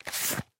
Извлекаем флешку из разъема